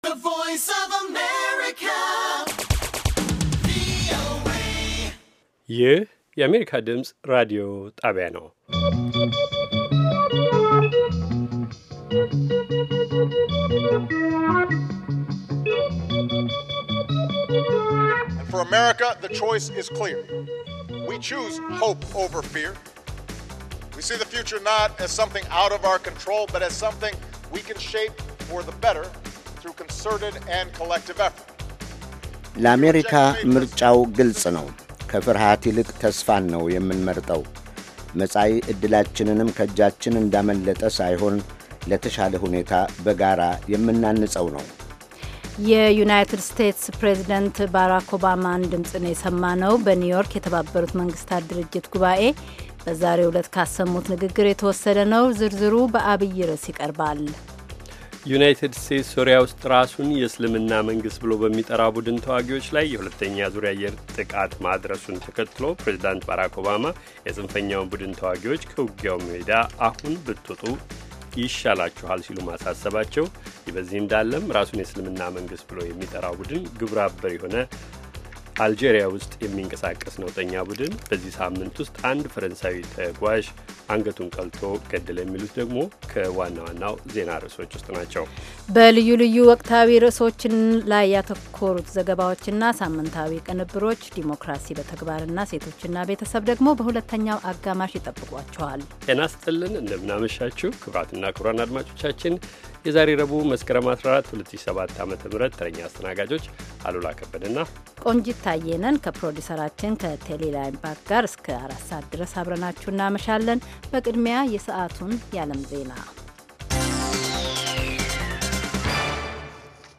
ዘወትር ከምሽቱ ሦስት ሰዓት ላይ ኢትዮጵያና ኤርትራ ውስጥ ለሚገኙ አድማጮች በአማርኛ የሚተላለፉ ዜናዎች፣ ቃለመጠይቆችና ሌሎችም ትኩስ ዘገባዎች፤ እንዲሁም በባሕል፣ በጤና፣ በሴቶች፣ በቤተሰብና በወጣቶች፣ በፖለቲካ፣ በግብርና፣ በንግድ፣ በተፈጥሮ አካባቢ፣ በሣይንስ፣ በቴክኖሎጂ፣ በስፖርት፣ በሌሎችም አካባቢያዊና የመላ አፍሪካ ጉዳዮች ላይ ያተኮሩ መደበኛ ዝግጅቶች የተካተቱባቸው የአንድ ሰዓት ዕለታዊ ሥርጭቶች